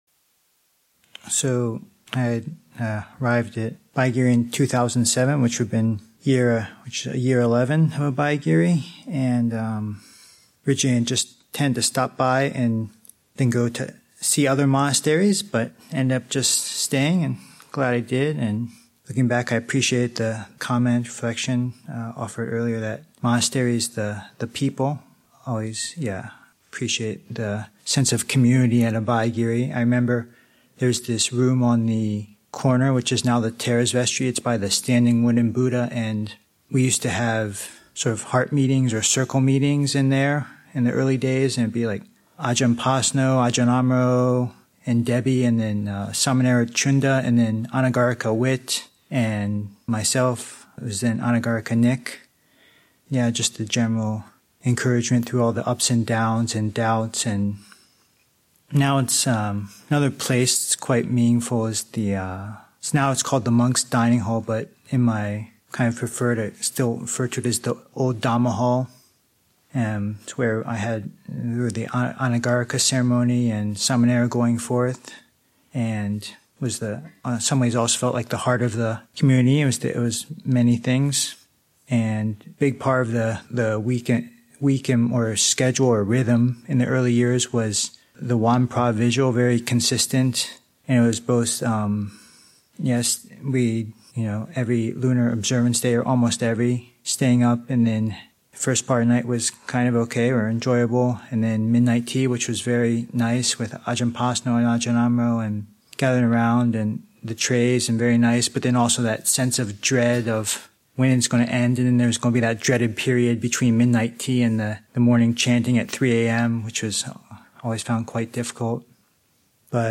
28th Anniversary Practice and Study Day, Session 6 – Jun. 1, 2024